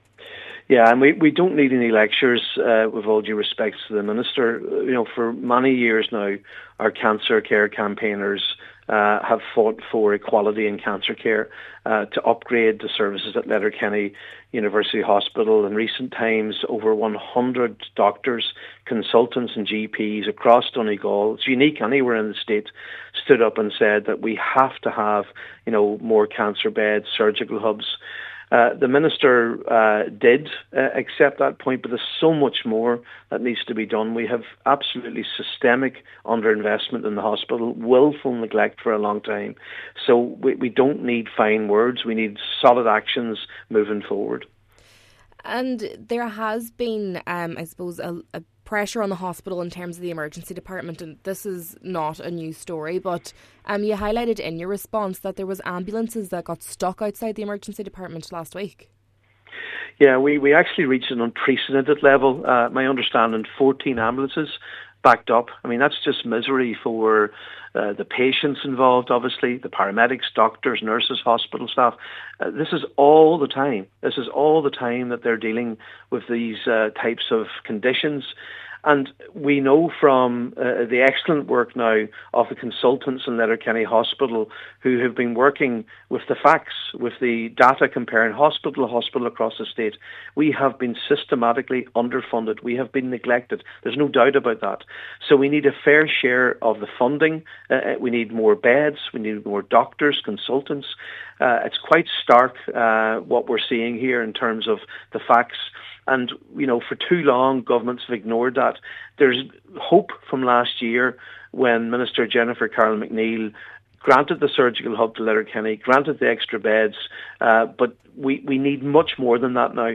The Sinn Féin TD says an admission of fault is the first step needed in an overhaul of the system: